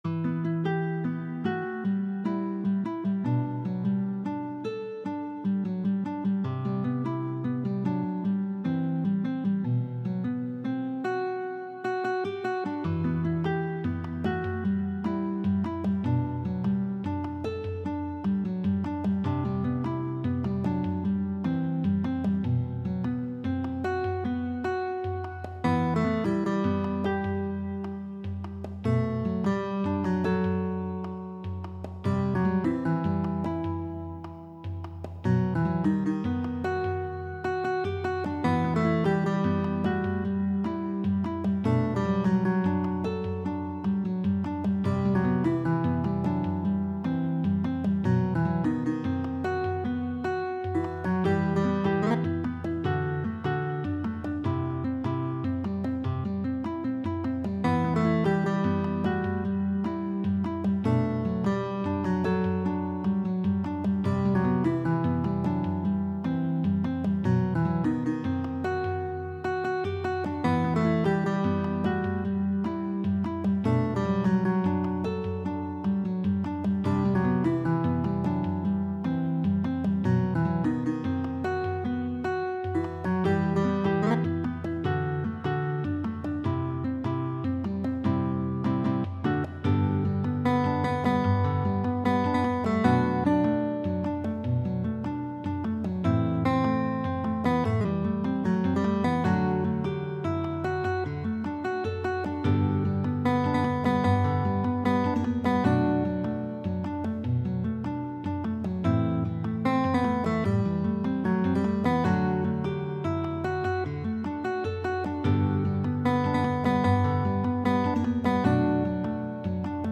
بکینگ ترک